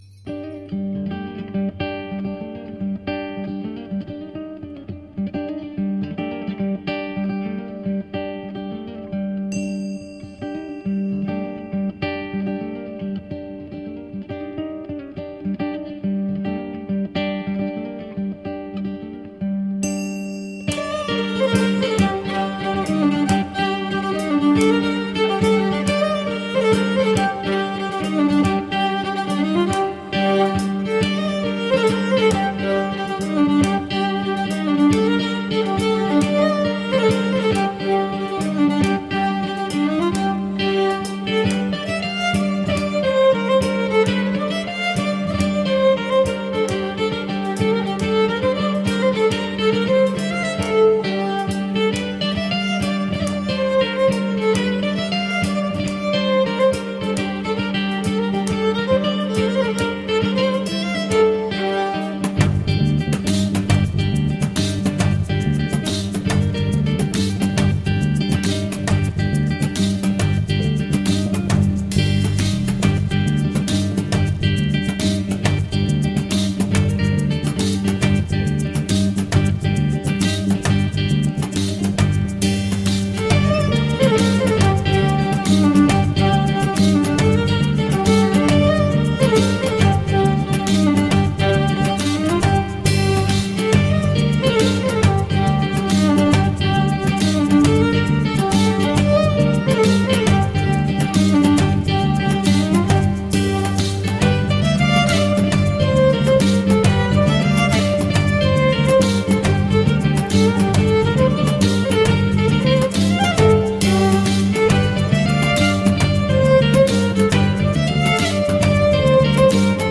Early music meets global folk at the penguin café.